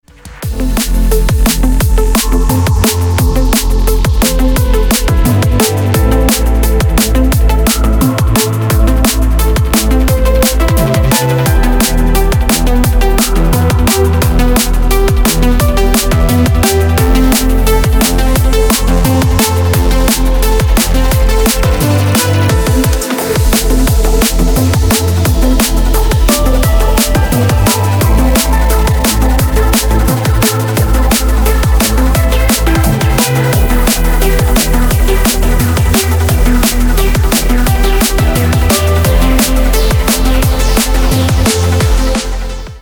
DubStep / DnB рингтоны